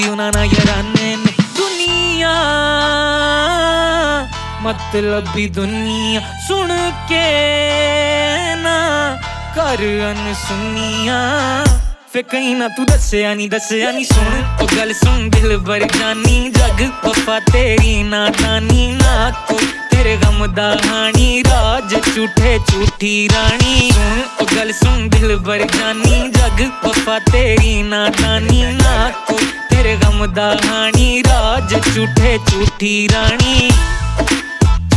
Жанр: Хип-Хоп / Рэп / Поп музыка